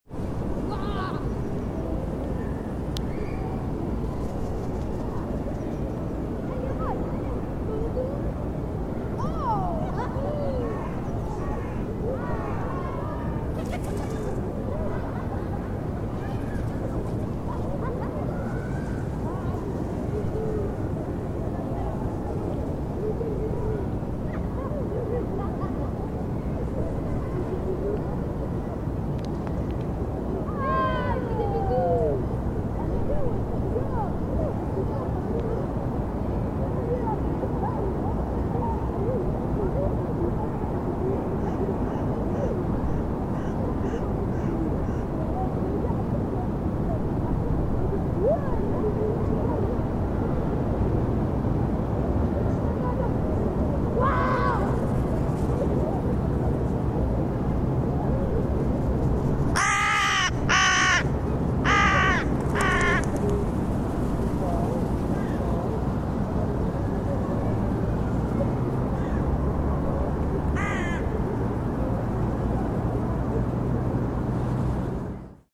Machinations of pigeons and rooks in St James' Park
One of those flocks of rooks was gathered round some discarded lunch in St James' Park, in London. I walked up and dropped a recorder on the ground for a minute to see what would happen